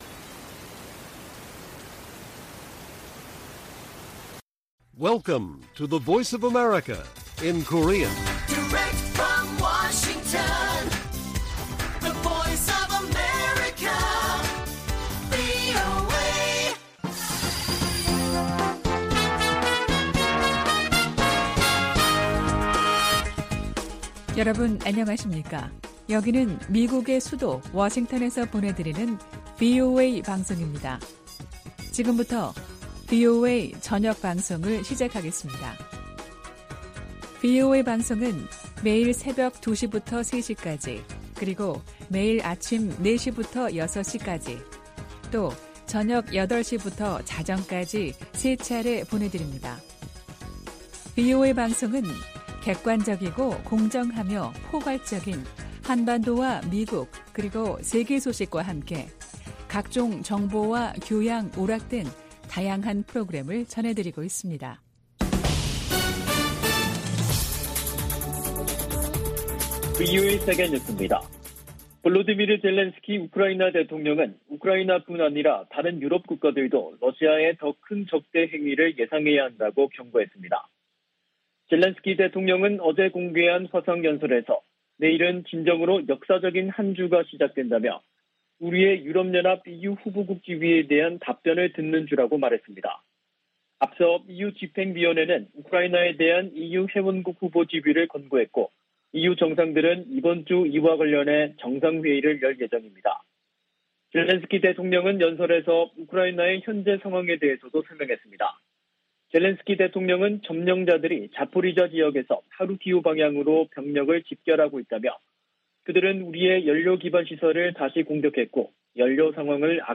VOA 한국어 간판 뉴스 프로그램 '뉴스 투데이', 2022년 6월 20일 1부 방송입니다. 미국의 핵 비확산 담당 고위 관리가 오는 8월 NPT 재검토 회의에서 북한 문제를 다룰 것을 요구했습니다. 북한의 7차 핵실험에 관해, 정치적 효과를 극대화하는 데 시간이 걸릴 수 있다는 관측이 나오고 있습니다. 유엔의 의사결정 구조 한계 때문에 북한의 행동을 바꾸기 위한 국제적 단합에 제동이 걸릴 것으로 미 의회조사국이 진단했습니다.